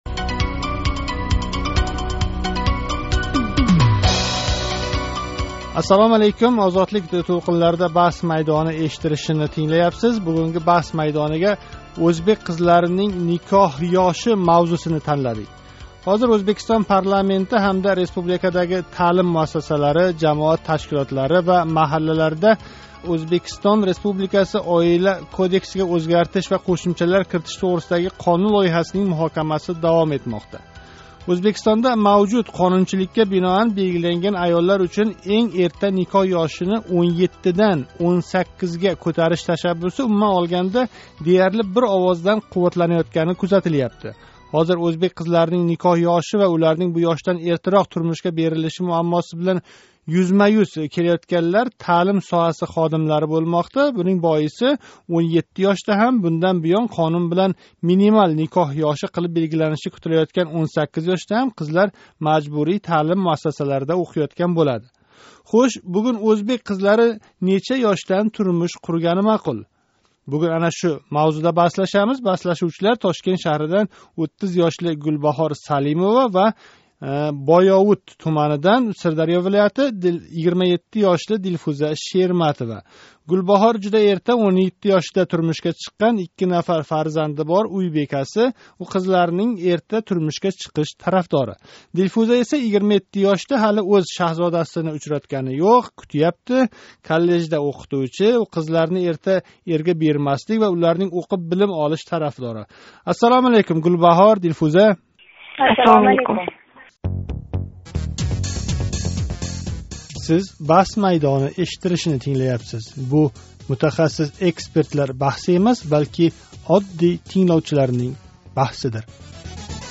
“Баҳс майдони”нинг бугунги сони иштирокчилари ўзбек қизлари неча ёшдан турмуш қуриши маъқул, деган мавзу атрофида мунозара қилди.